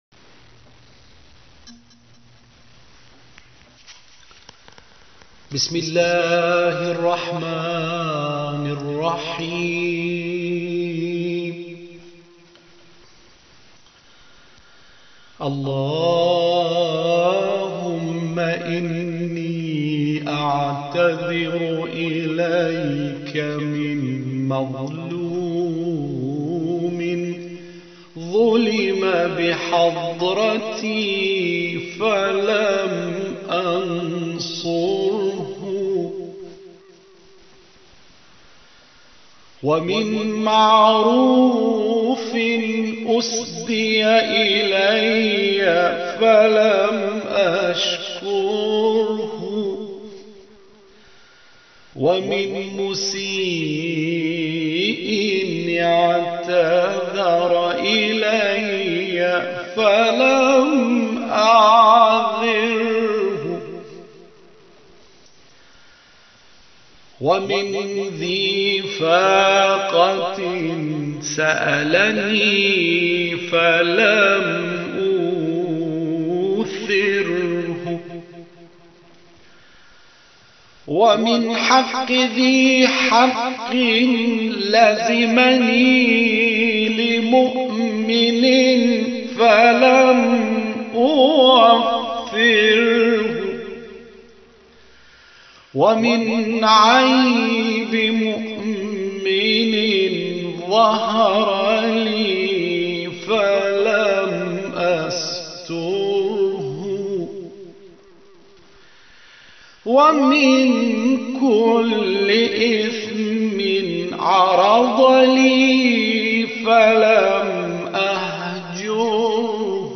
اجرای دعای 38 صحیفه سجادیه + صوت